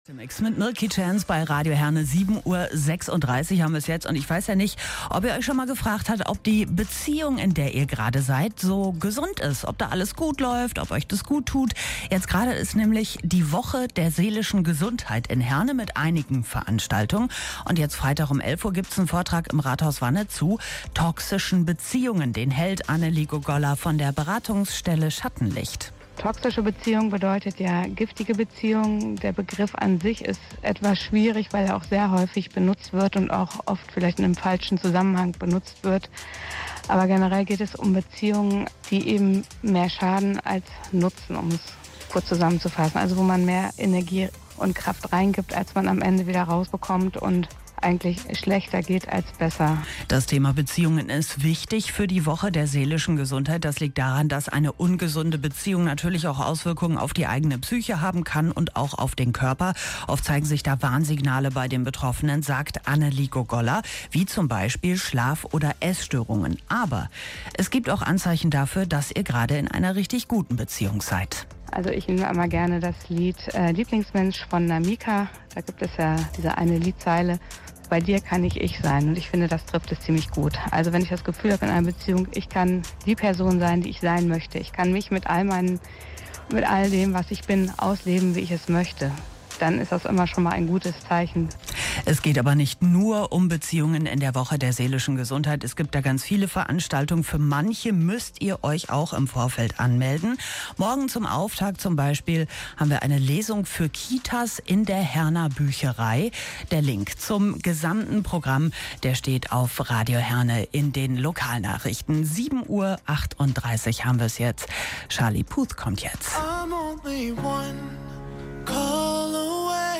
Ein Interview
Radiobeitrag_Oktober_2023.mp3